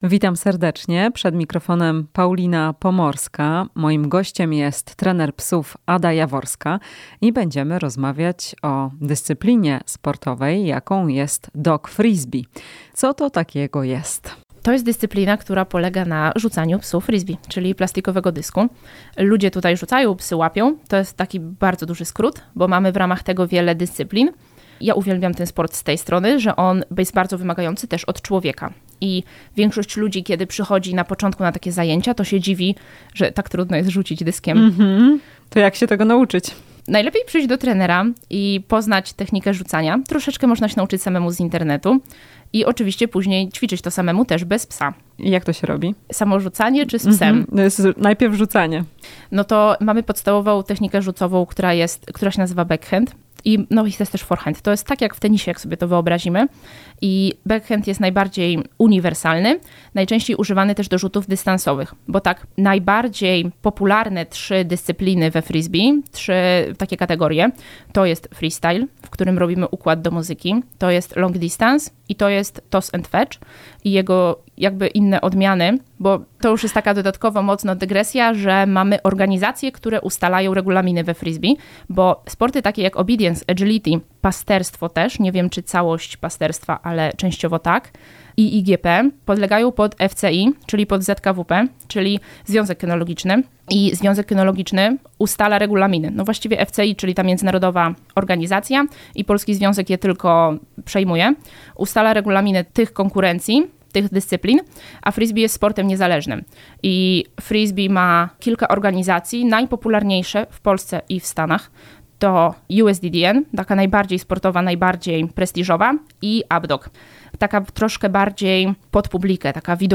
W "Chwili dla pupila" wyjaśniamy czym jest dog frisbee i dla jakich psów będzie to idealny sport. Rozmowa z trenerem